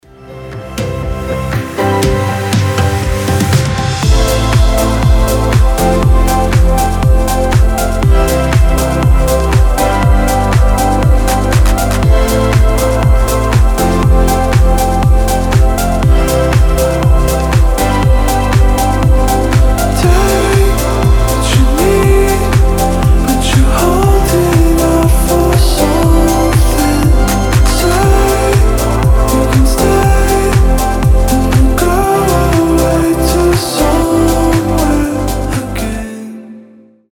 • Качество: 320, Stereo
мелодичные
Electronic
chillout